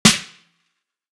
Sizzle Marbles.wav